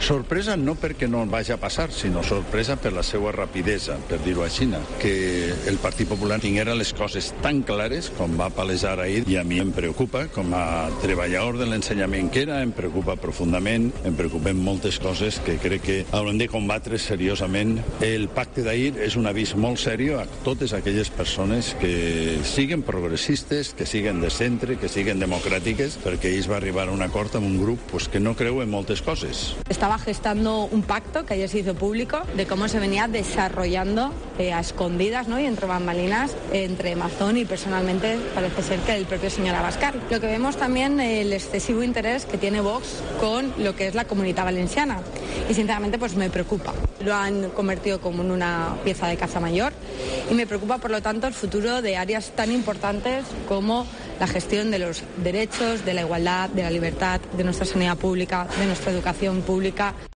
El PP ha llegado a un acuerdo "con un grupo que no cree en muchas cosas, desde que hay violencia de género al cambio climático, cosas que ayer se empezaban a juzgar en Estados Unidos y que tienen su variante aquí", ha dicho a los periodistas.